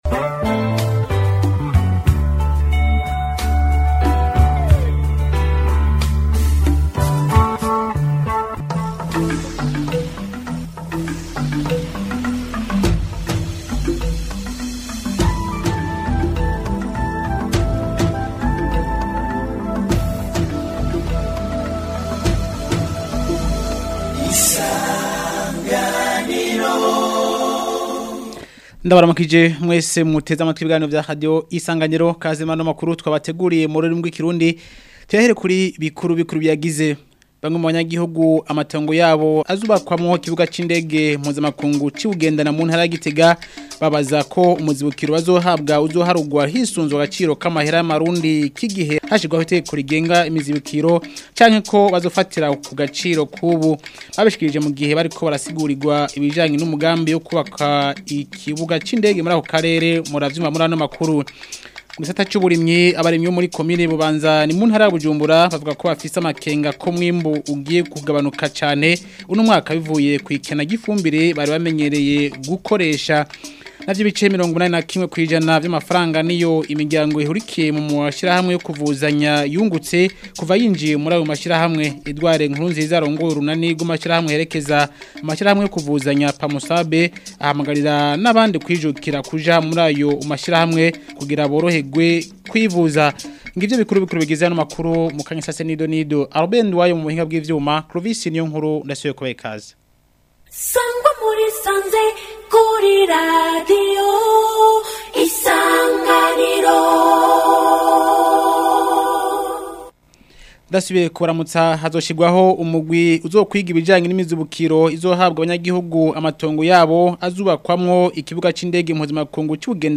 Amakuru yo ku wa 13 Kigarama 2025